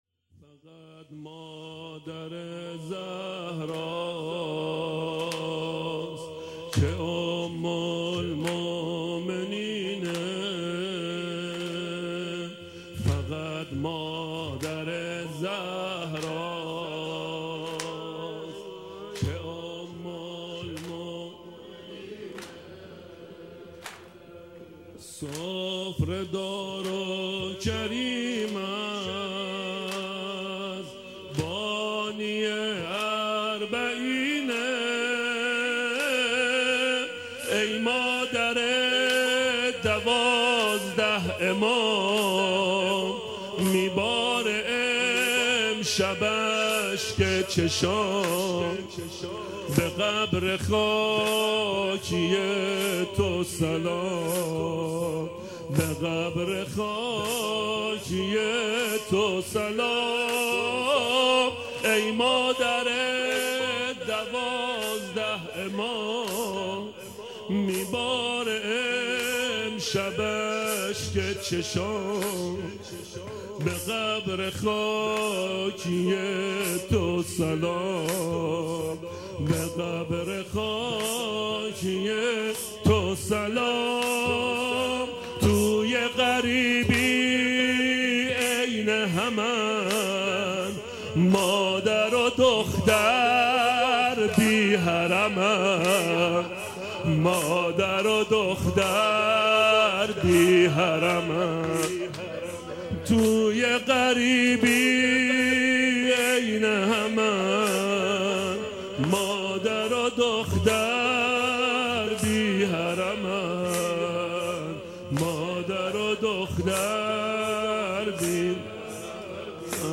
روضه حضرت خدیجه سلام الله علیها